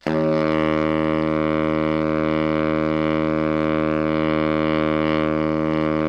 BARI PP D#1.wav